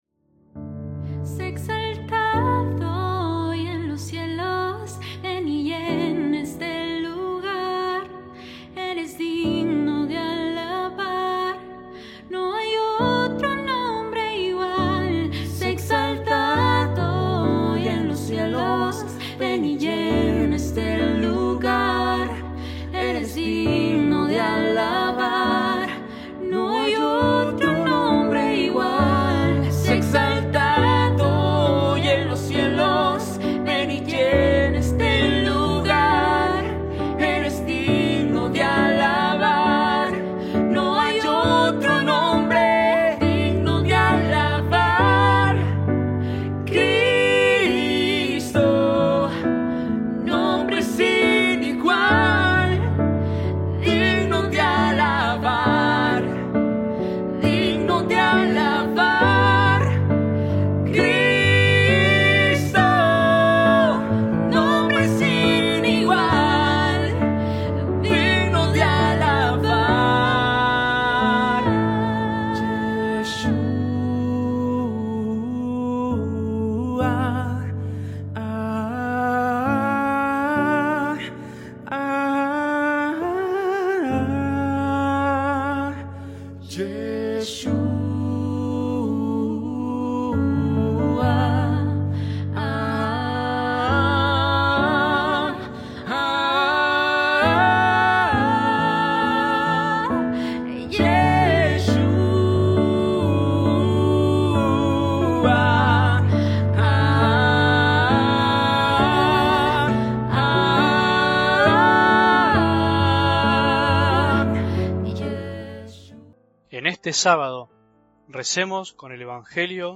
ESCUCHAR ORACIÓN : Música: San Martín de Tours